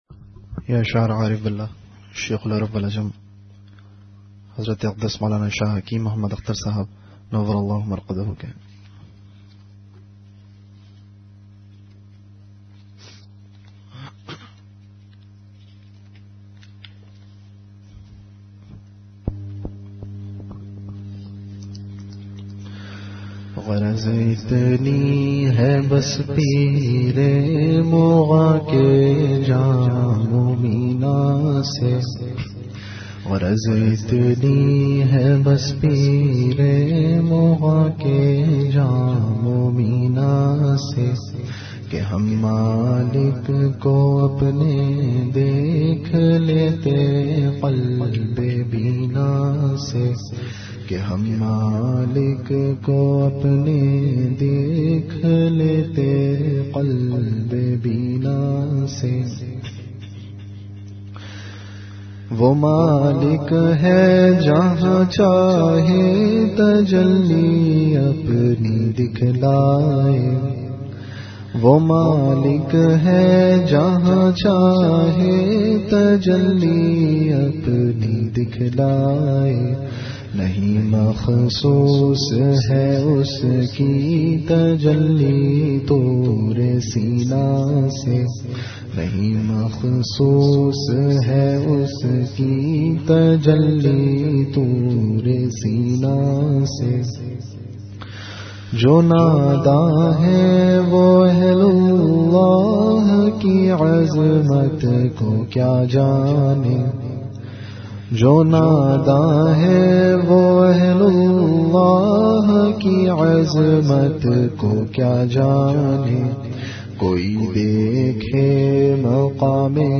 An Islamic audio bayan
Majlis-e-Zikr